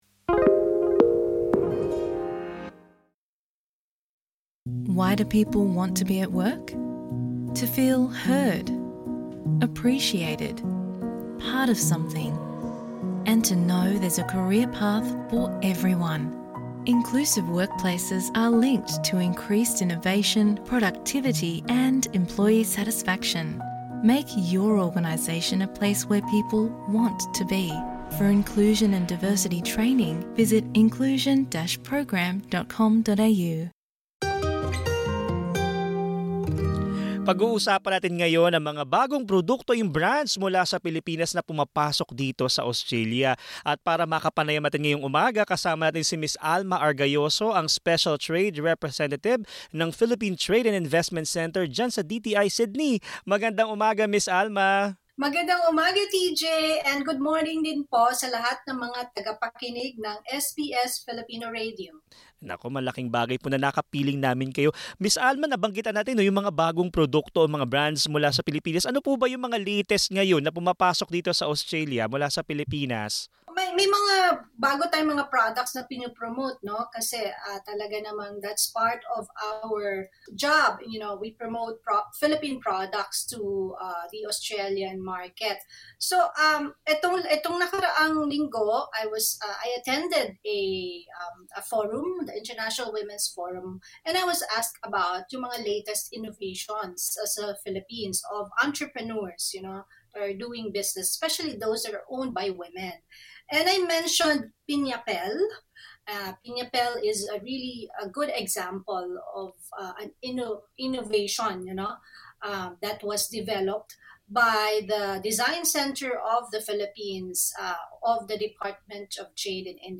Ibinahagi sa panayam